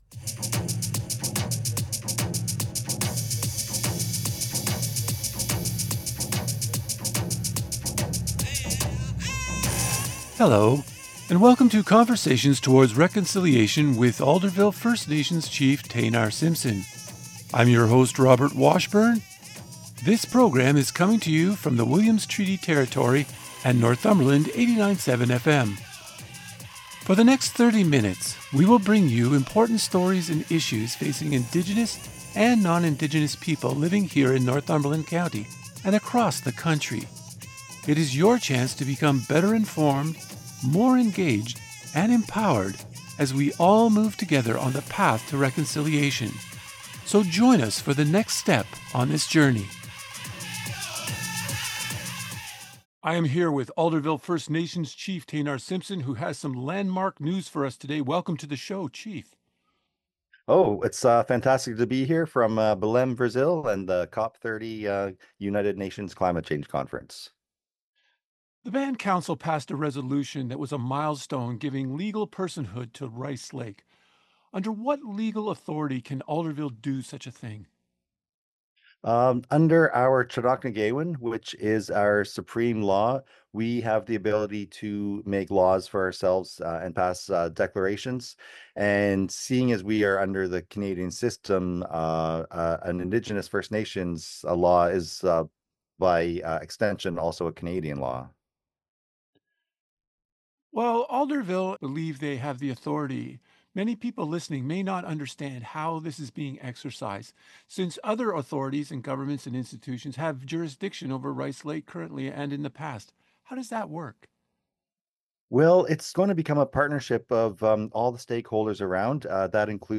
Alderville First Nations Chief Taynar Simpson is on the show today to explain in detail what this will mean.